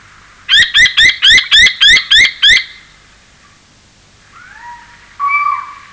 Сыч домовый (Athene noctua)
Athene-noctua.wav